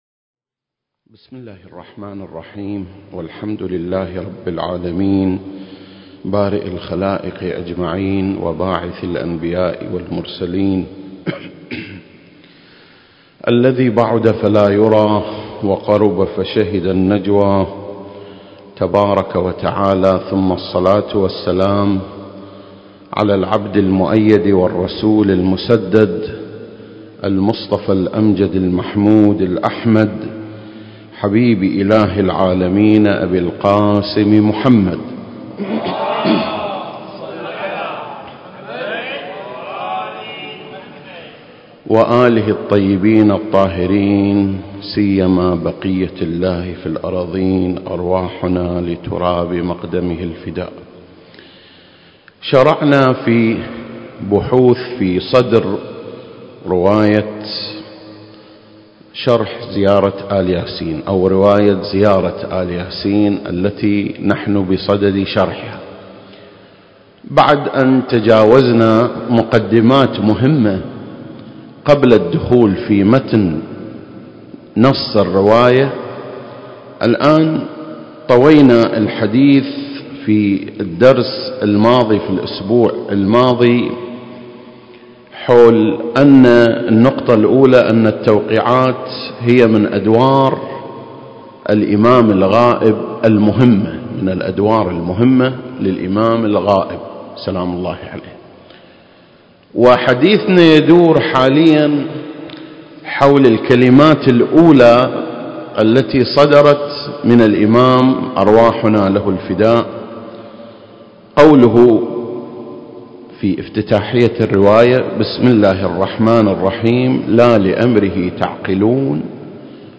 سلسلة: شرح زيارة آل ياسين (12) - المواضيع التي تناولتها التوقيعات المكان: مسجد مقامس - الكويت التاريخ: 2021